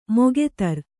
♪ mogetar